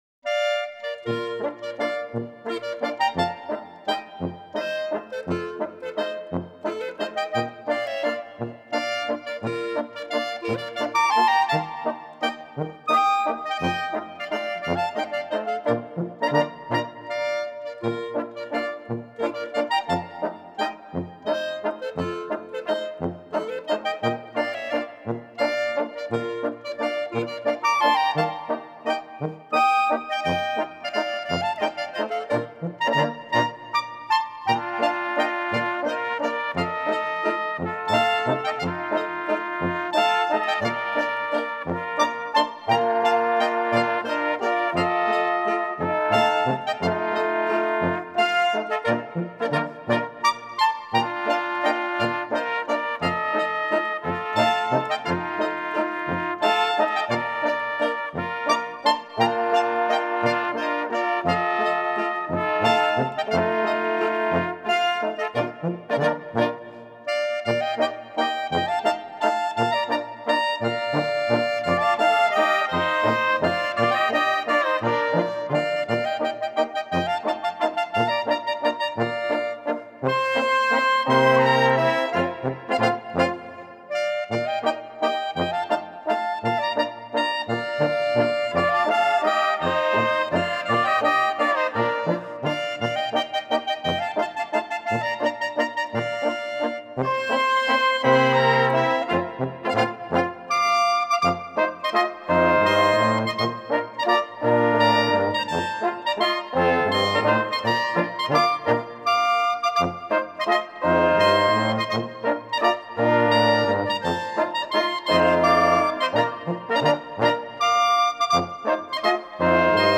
Jodler, Jodler-Lied, Gstanzl und Tanz
Folk & traditional music